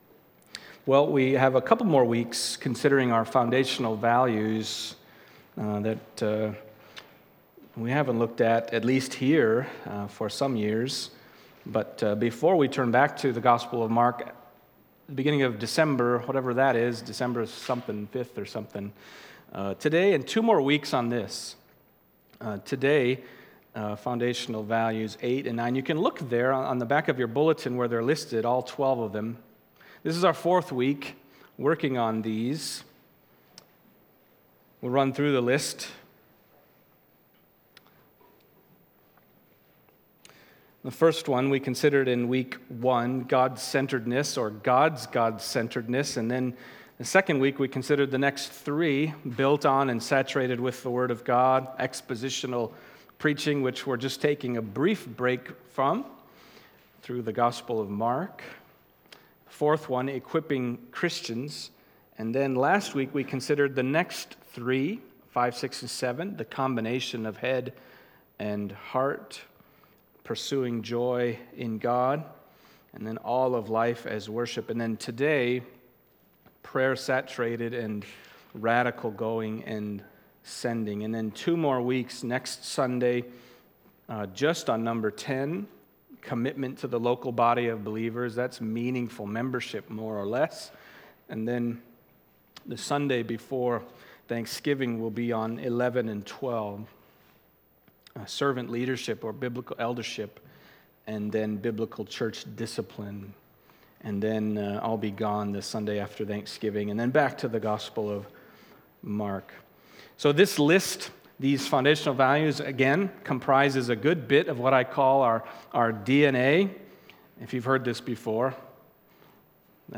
Foundational Values Service Type: Sunday Morning 8.